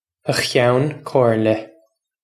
Pronunciation for how to say
uh Khyown Kore-lyah